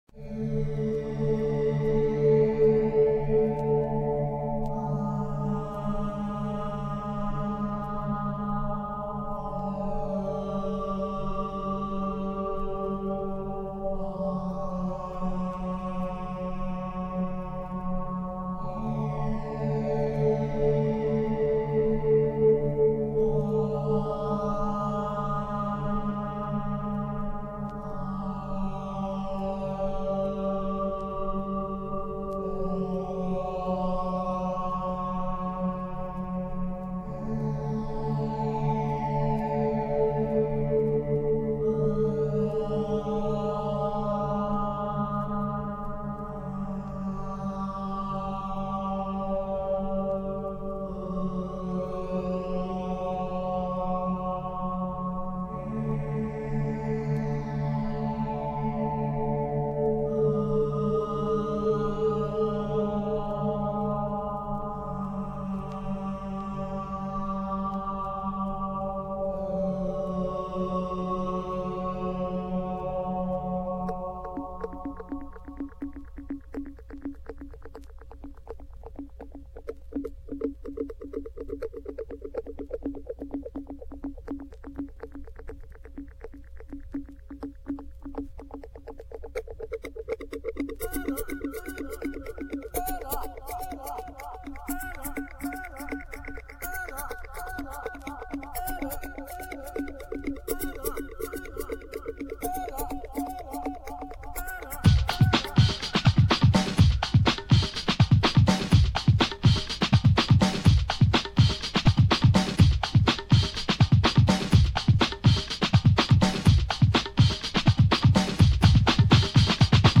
Packed full of the same rhythmic melodies